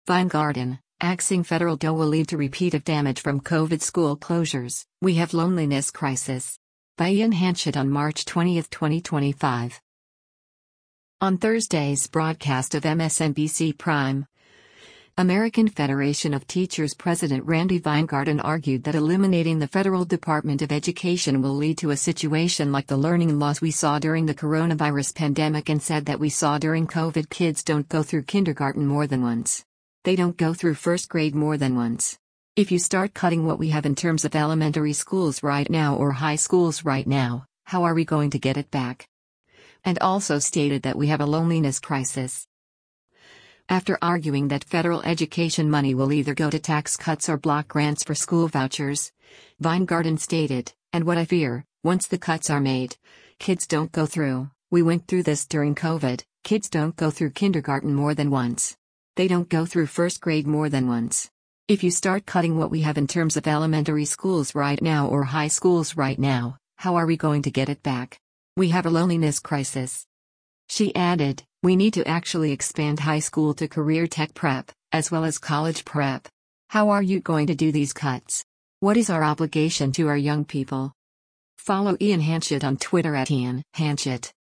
On Thursday’s broadcast of “MSNBC Prime,” American Federation of Teachers President Randi Weingarten argued that eliminating the federal Department of Education will lead to a situation like the learning loss we saw during the coronavirus pandemic and said that we saw during COVID “kids don’t go through kindergarten more than once.